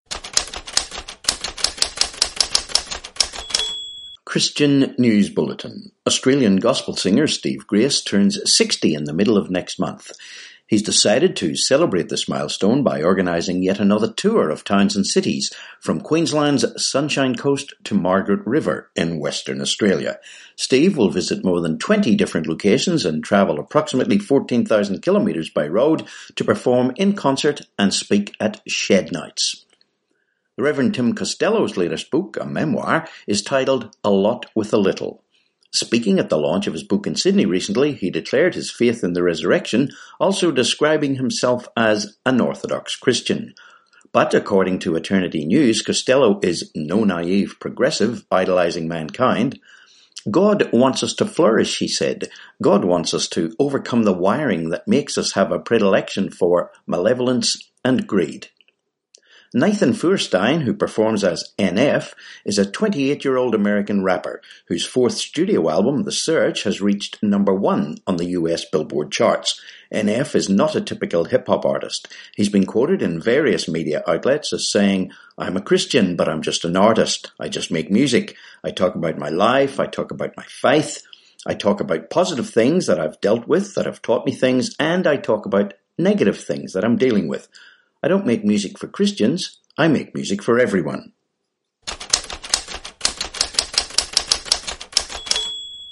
18Aug19 Christian News Bulletin